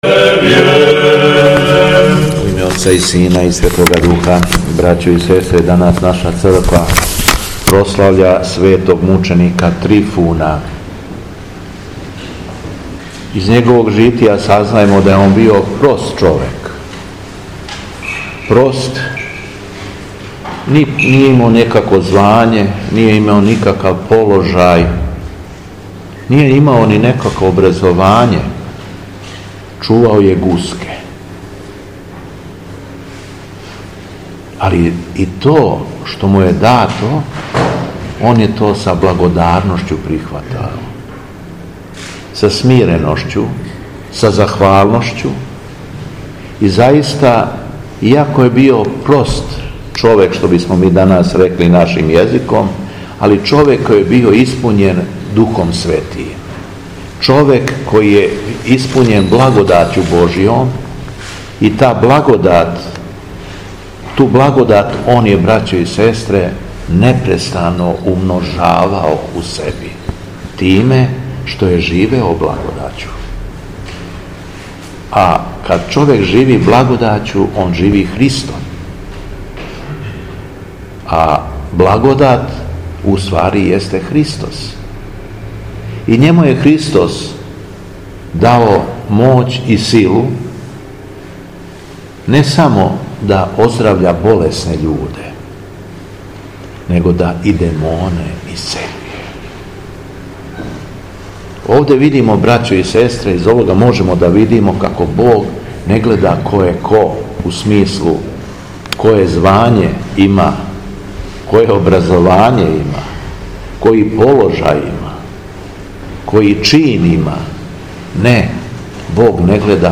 Беседа Његовог Преосвештенства Епископа шумадијског г. Јована
По прочитаном јеванђелском зачалу, Преосвећени Владика Јован се обратио верном народу беседом: